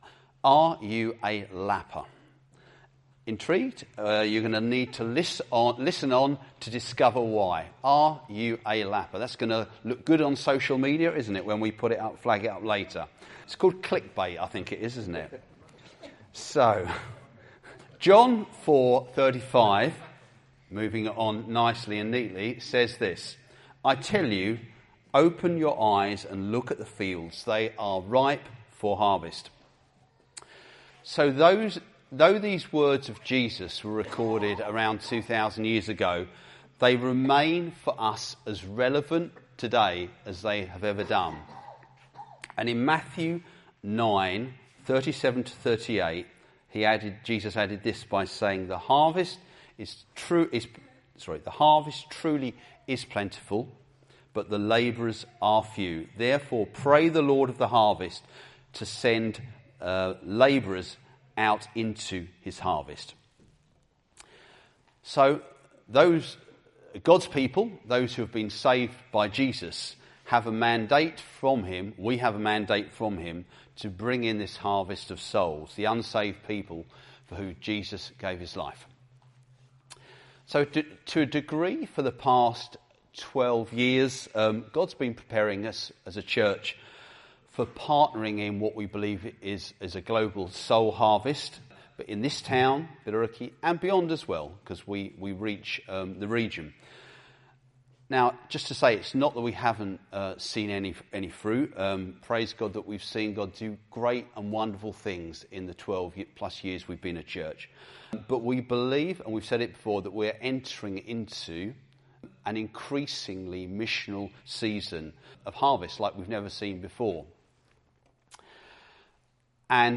Teachings from our Sunday that don’t form part of a series.